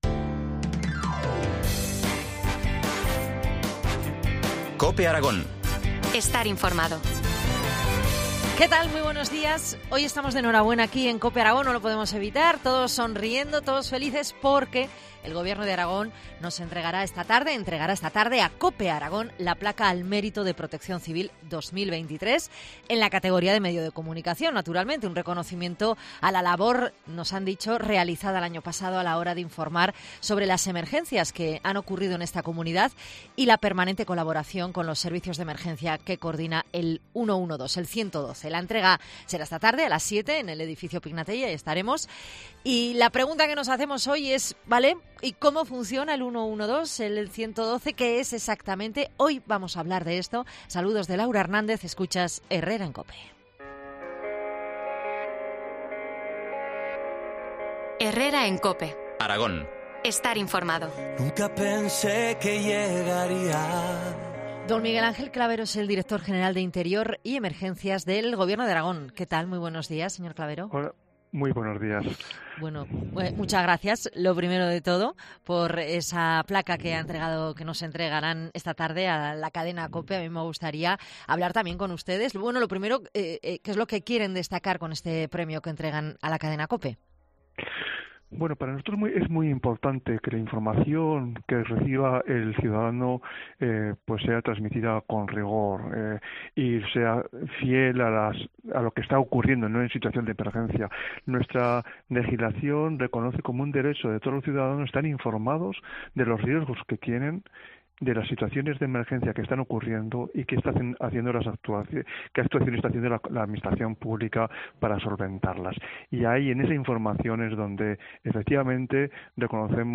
Entrevista a Miguel Ángel Clavero, director general de Emergencias del Gobierno de Aragón